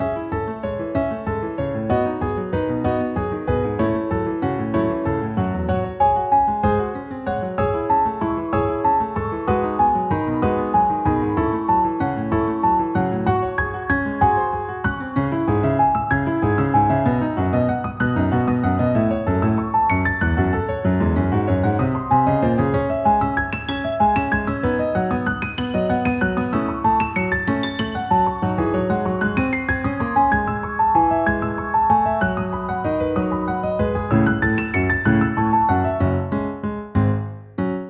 A 3-4 timed tune I intended for a classical ballroom. I intended for it to be a little ghoulish but needs more minor keys I think. It could use a part B that builds on the initial flow as the groove ends abruptly.
apparitions_ball_-_piano.ogg